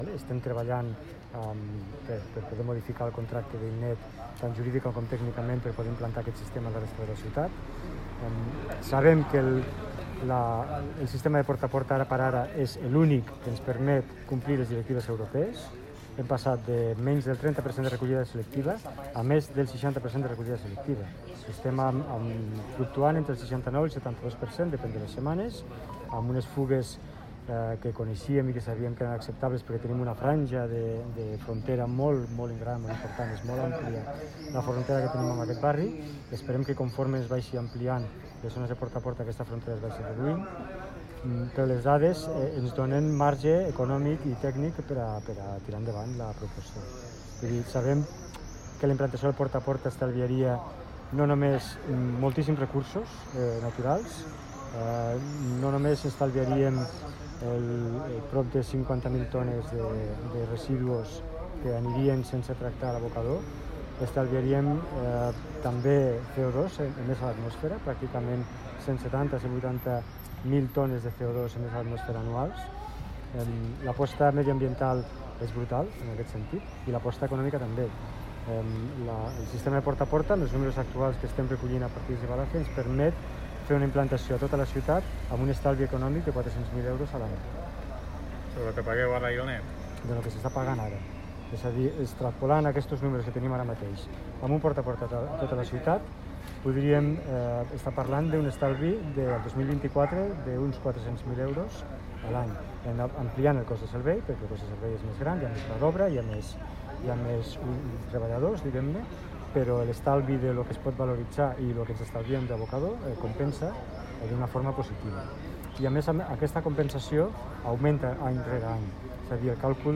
tall-de-veu-del-tinent-dalcalde-sergi-talamonte-sobre-la-progressiva-implantacio-de-la-recollida-selectiva-a-lleida